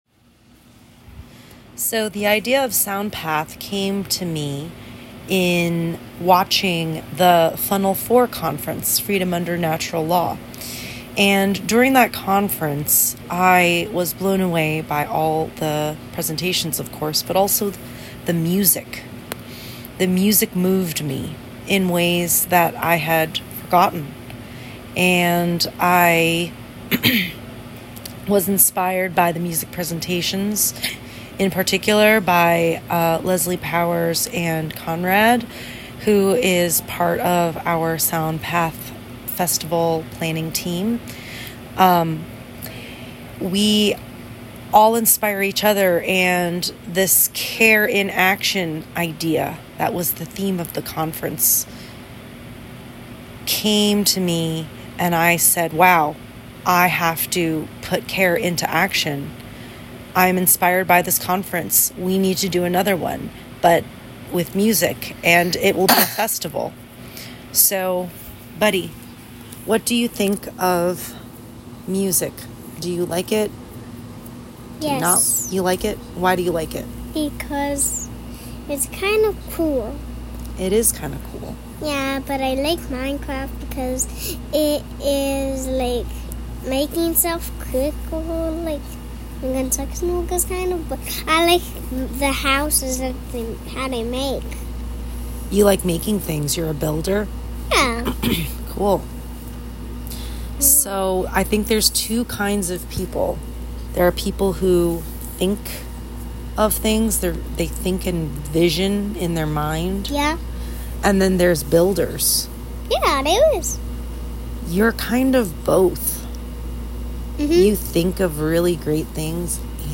Audio Promo